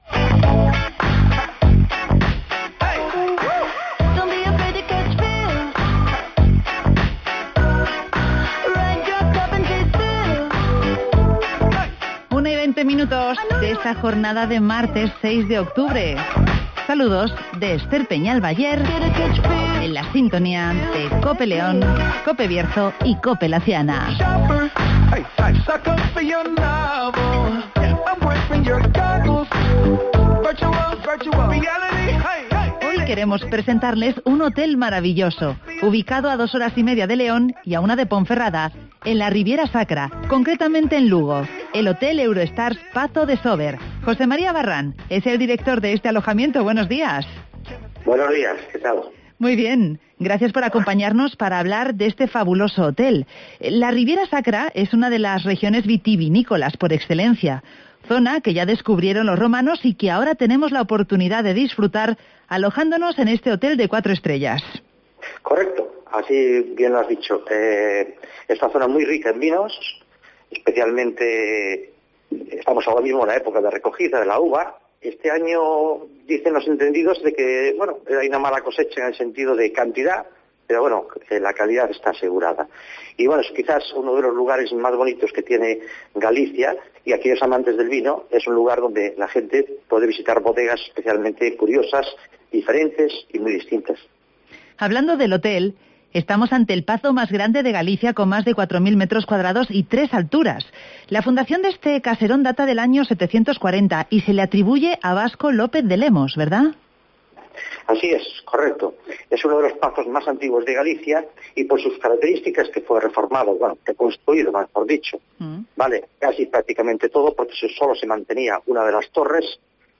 Visitamos el Pazo de Sober, en Lugo, un hotel con spa rodeado de un paisaje espectacular en la Riviera Sacra (Entrevista